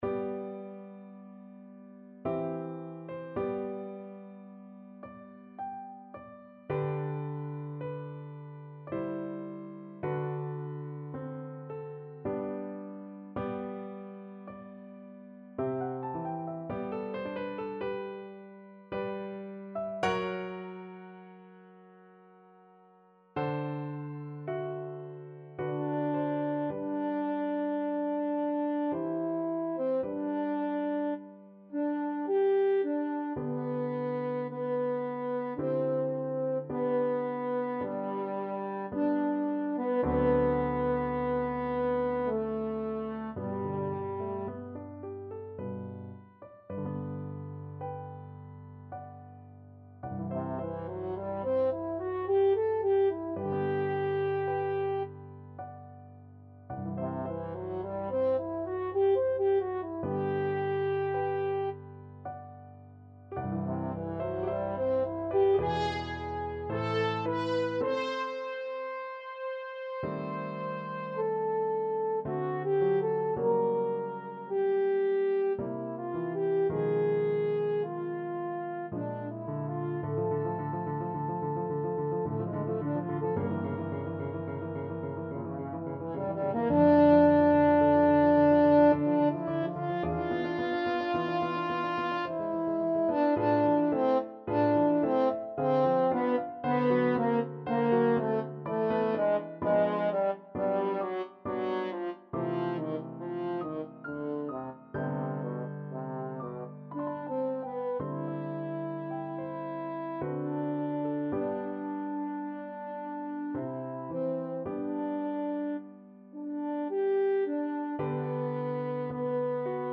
Free Sheet music for French Horn
French Horn
3/4 (View more 3/4 Music)
G major (Sounding Pitch) D major (French Horn in F) (View more G major Music for French Horn )
Adagio ma non troppo =108